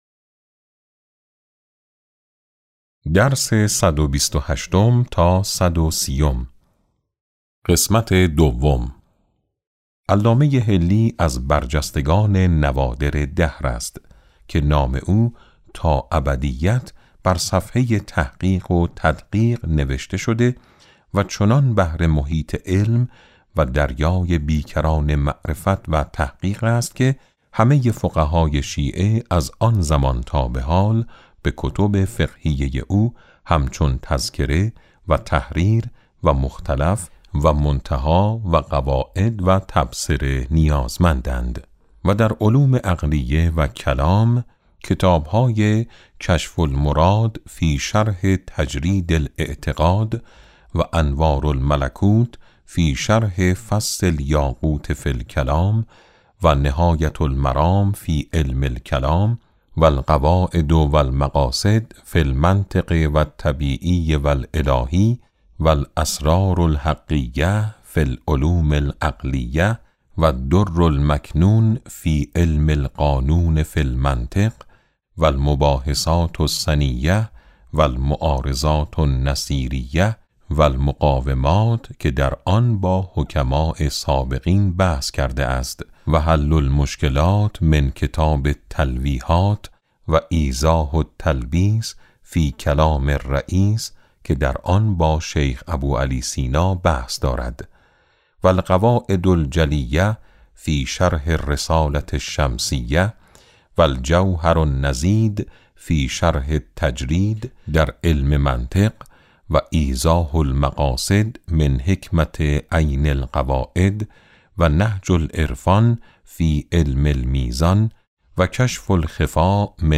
کتاب صوتی امام شناسی ج9 - جلسه7